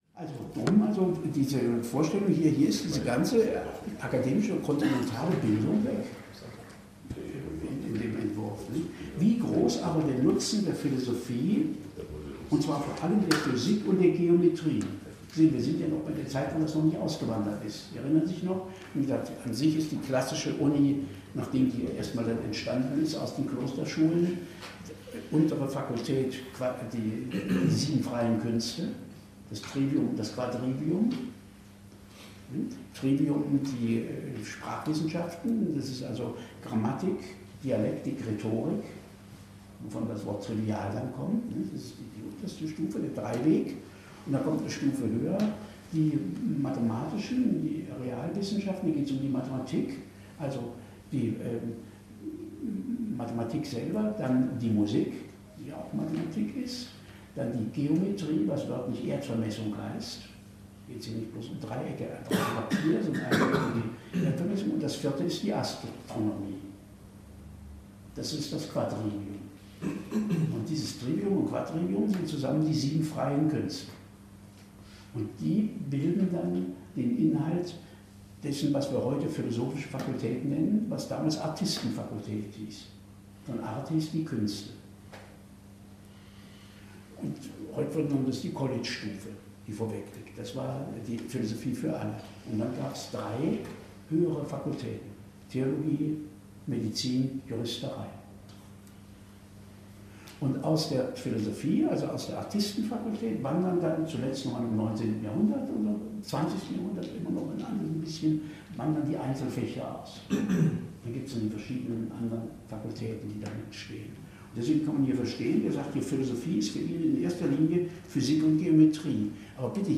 - Bemerkungen zu Vernunft und Tradition. - Homo homini lupus; Notwendigkeit des Rechts. Lektüreseminar.